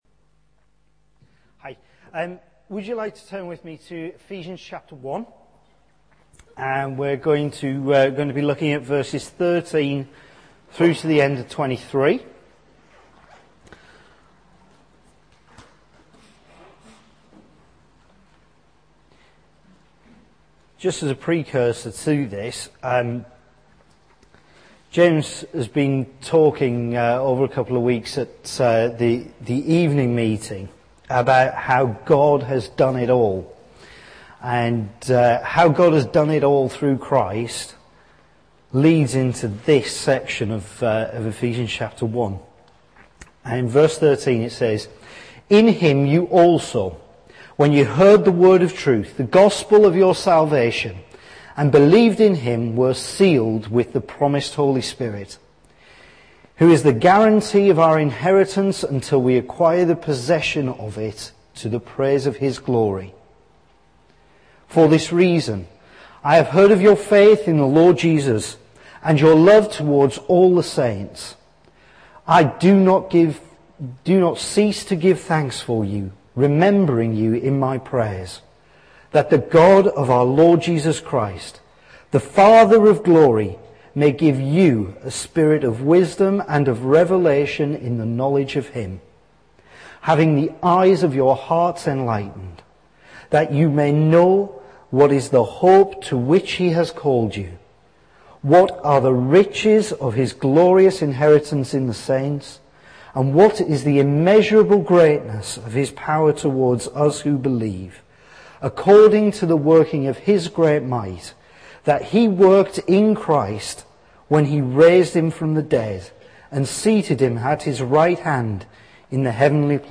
Talks from Emmanuel Church Durham's Days of Promise meetings, featuring teaching, prayer and ministry.